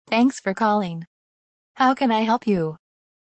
customer-service-tts-example-1-beth.mp3